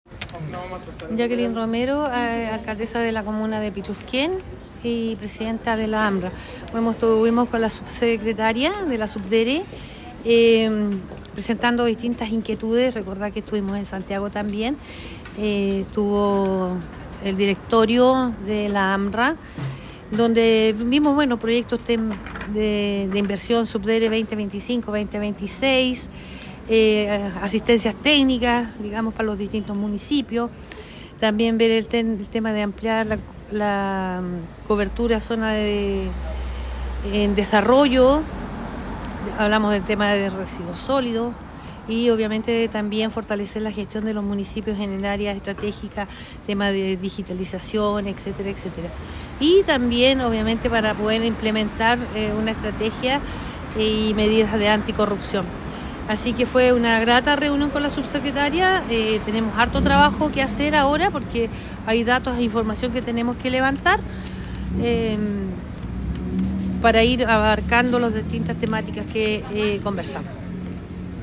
PUNTO-DE-PRENSA-SUBDERE-1-online-audio-converter.com_.mp3